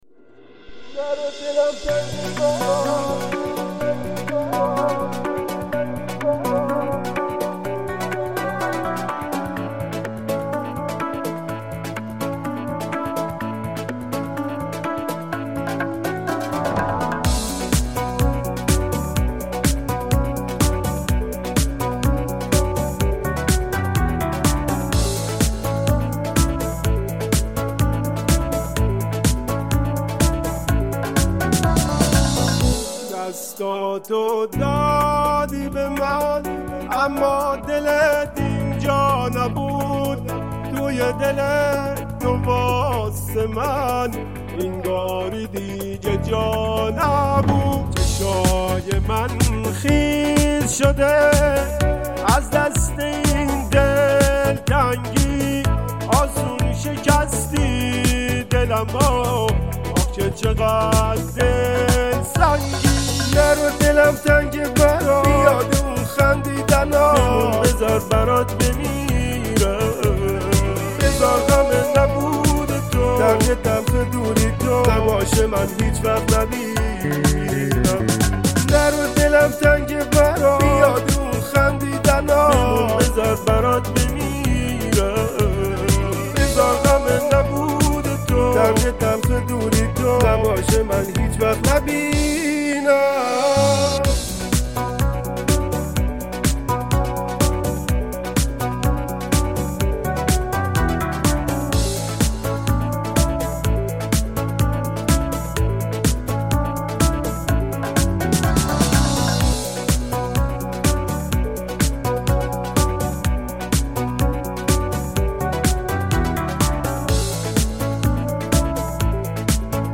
خواننده آهنگ